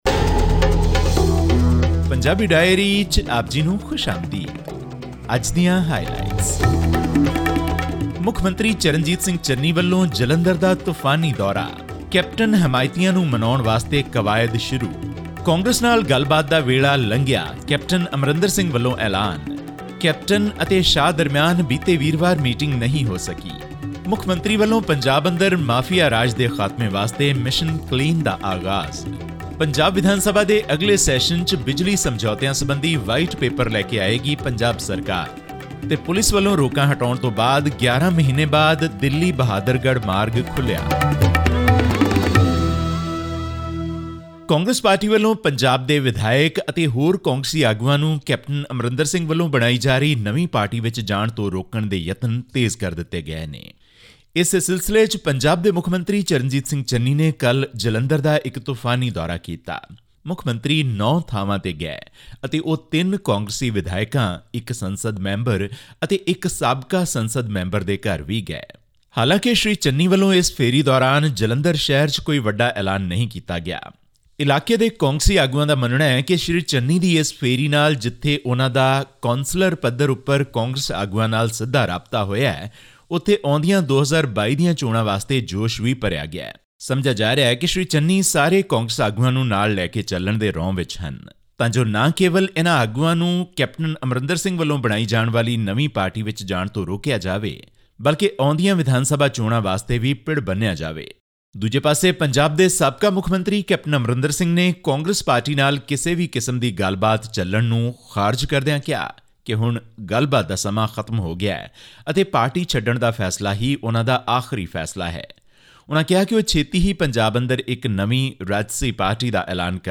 Chief Minister Charanjit Singh Channi directed officials to adopt a zero-tolerance policy to corruption and illicit trade in a meeting held with all the state's Deputy Commissioners and the Senior Superintendents of Police (SSPs) on 31 October. This and more in our weekly news update from Punjab.